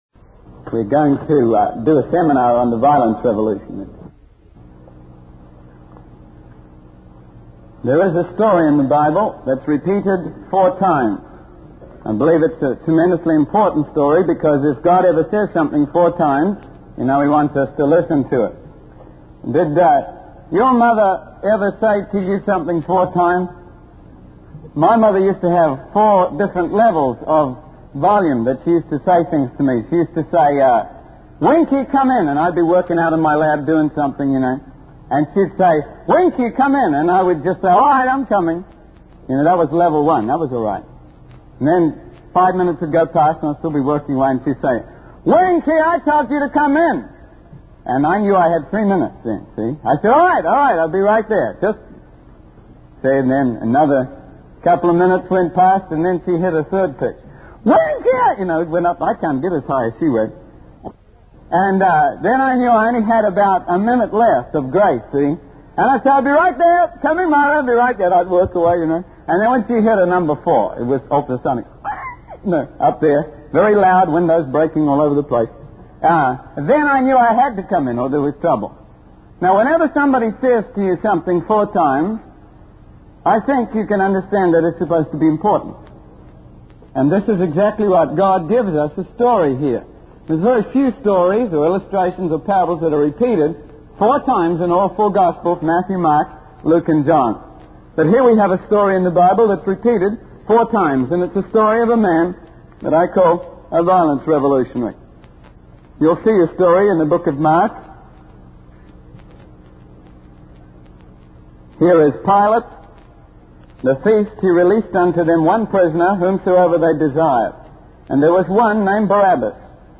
In this sermon, the speaker emphasizes the importance of paying attention to stories that are repeated four times in the Bible.